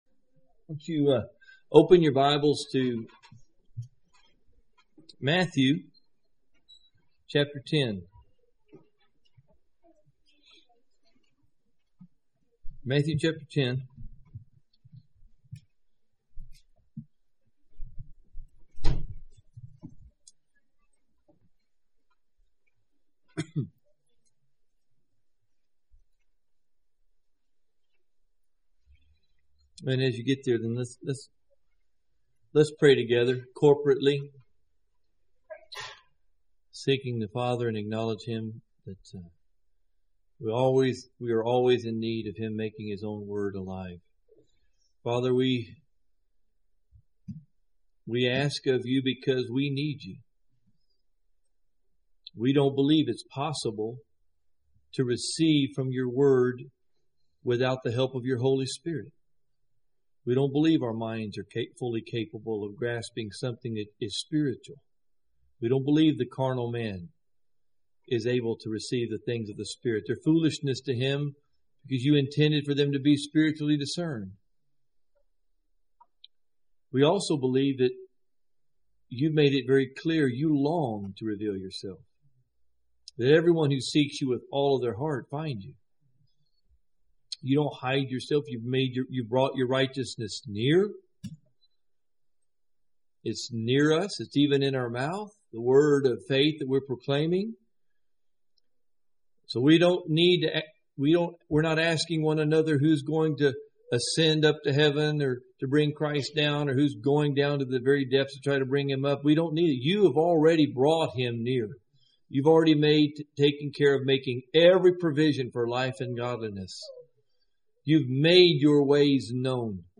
In this sermon, the speaker begins by praying for the Word of God to have a powerful impact on the listeners. He then poses the question of what the Great Commission is, emphasizing the importance of sharing the gospel and making disciples. The speaker then focuses on the topic of prayer, using a parable from Matthew 10 to highlight the significance of asking God for what we need in order to fulfill our calling.